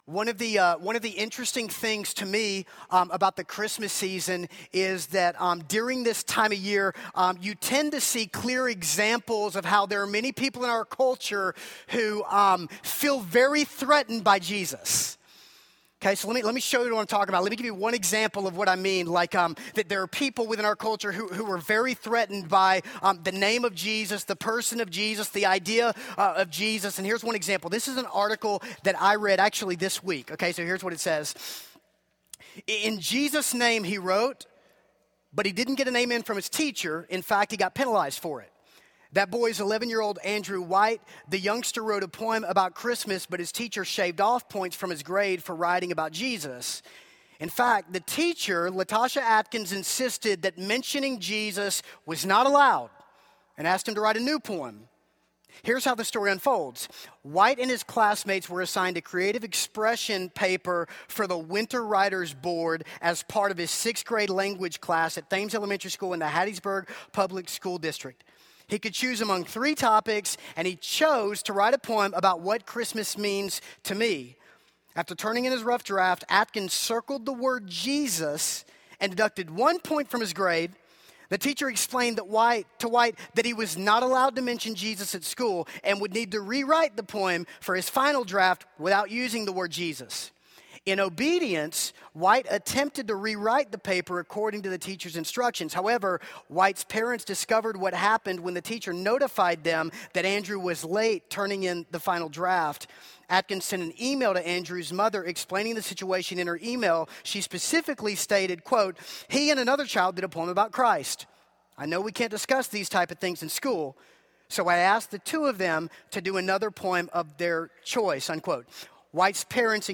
A Christmas Sermon - Matthew 1:18-2:23